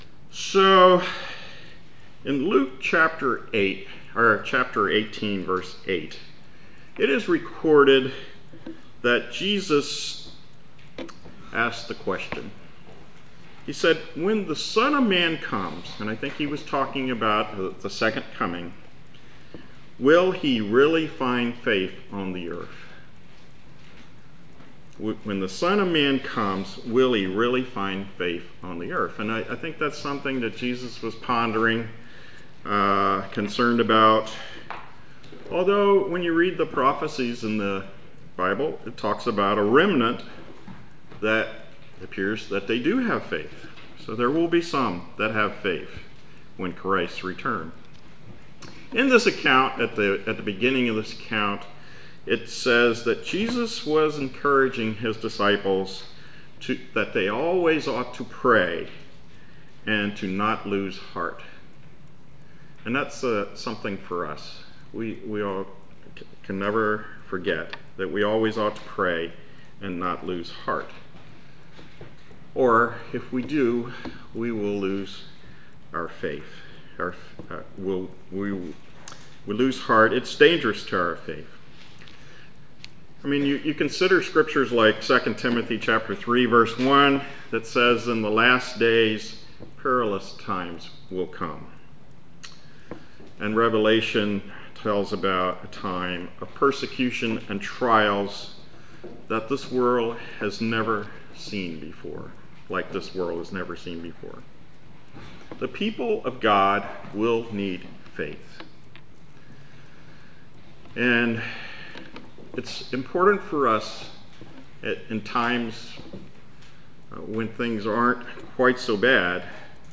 Sermons
Given in Lewistown, PA York, PA